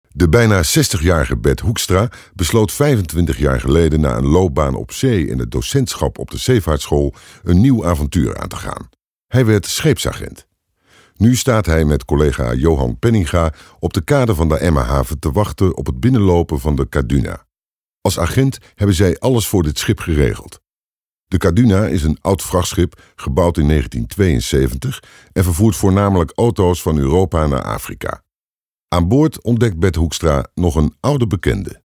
Deep, warm & trustworthy experienced voice over with impact.
Sprechprobe: Industrie (Muttersprache):